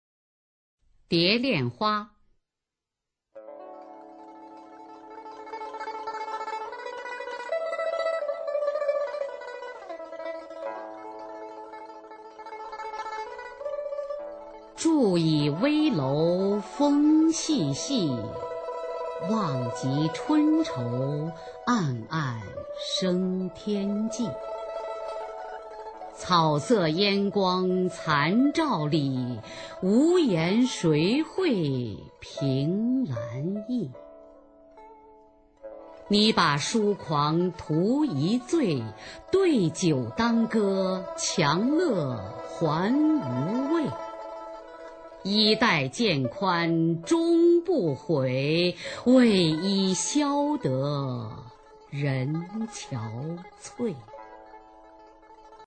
[宋代诗词朗诵]柳永-蝶恋花 古诗词诵读